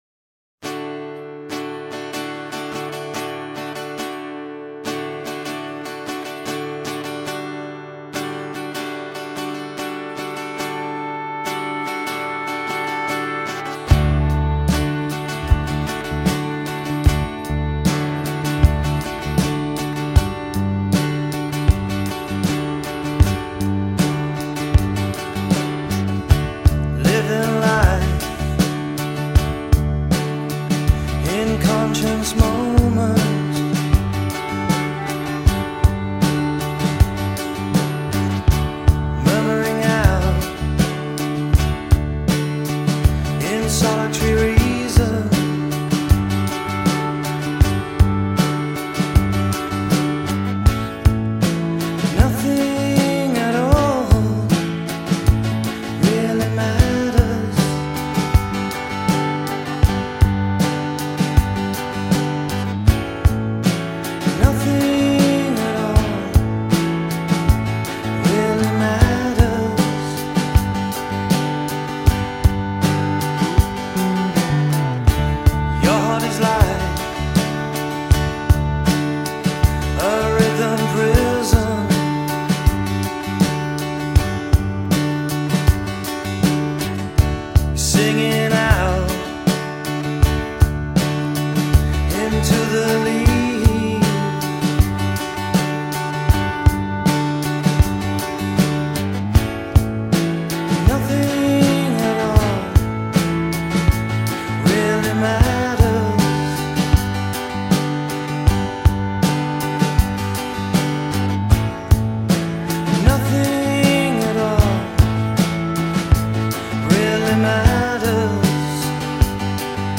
Drums
Bass
Vocals
Guitars
Harp